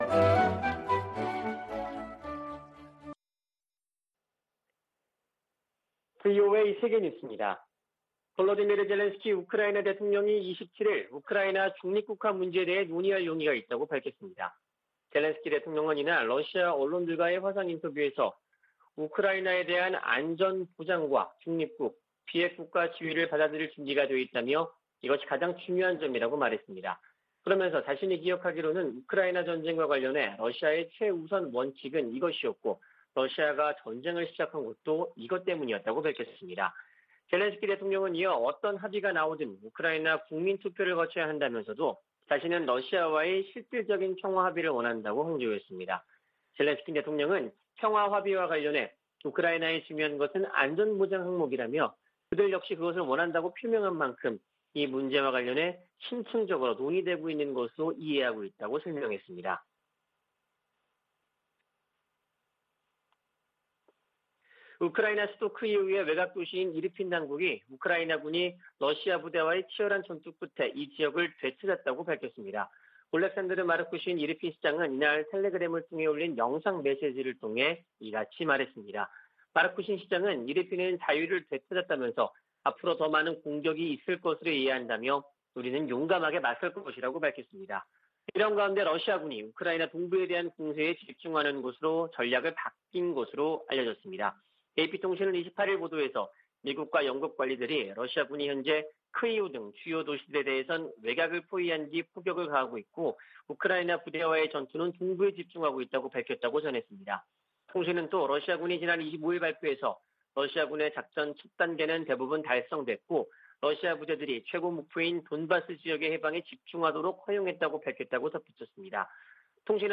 VOA 한국어 아침 뉴스 프로그램 '워싱턴 뉴스 광장' 2022년 3월 29일 방송입니다. 유엔 안보리가 북한 ICBM 발사에 대응한 공개 회의를 개최하고 규탄했으나 언론 성명 채택조차 무산됐습니다.